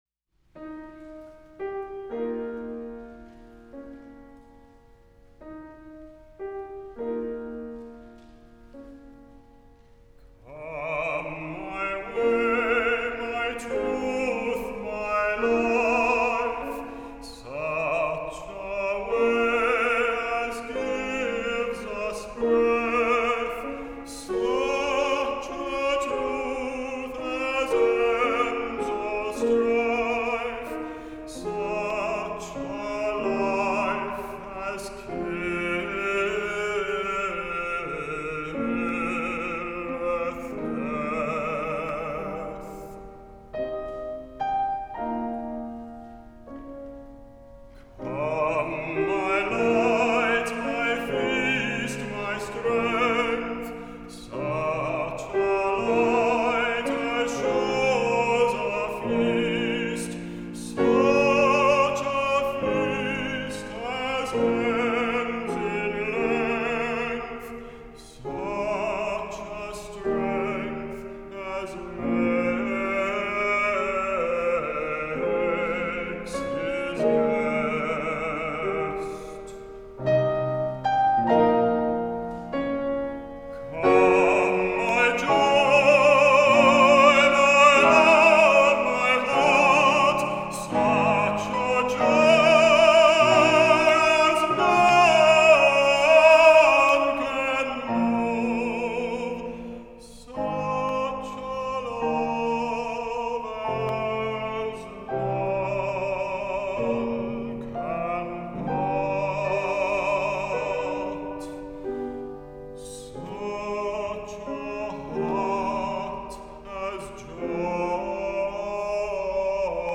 Recorded live in concert at Calvary Presybyterian Church, San Fransisco, California